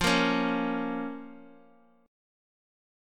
Fsus4#5 chord